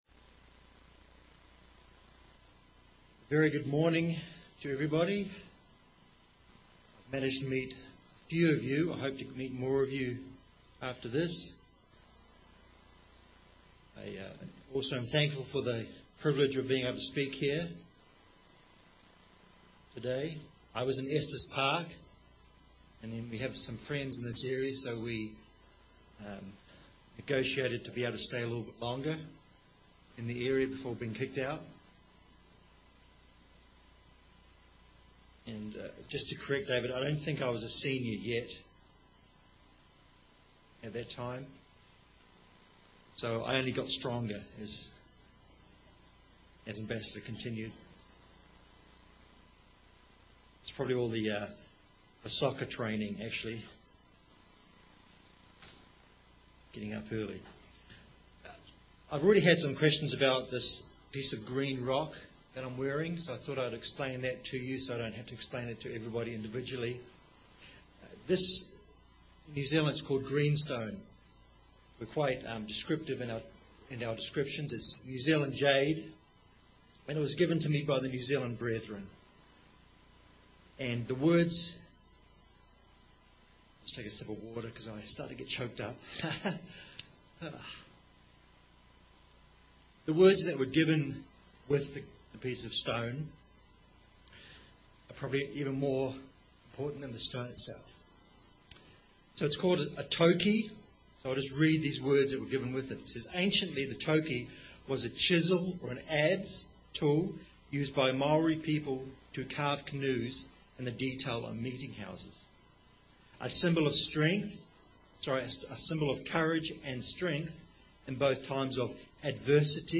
This sermon looks at some examples of the healings Jesus Christ performed which point to the future healing, the complete healing, of mankind. God’s plan of salvation involves complete healing.